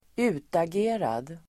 Ladda ner uttalet
Uttal: [²'u:tage:rad]